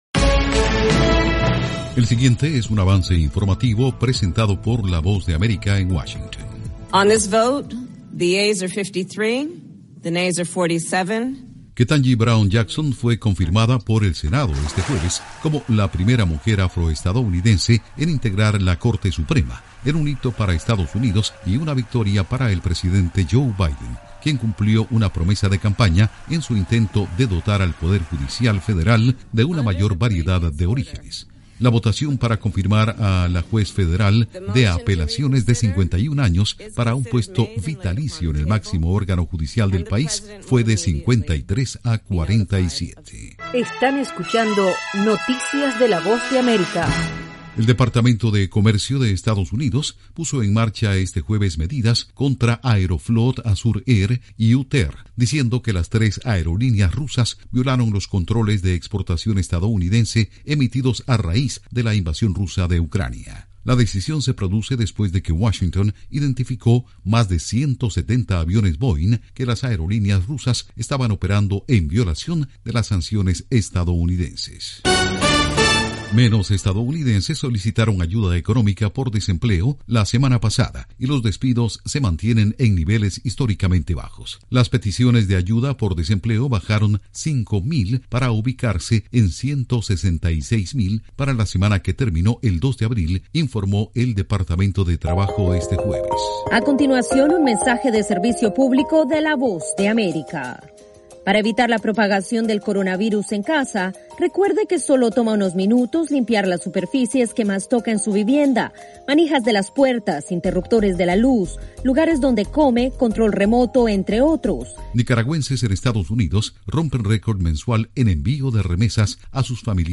Avance Informativo 7:00pm
El siguiente es un avance informativo presentado por la Voz de América en Washington.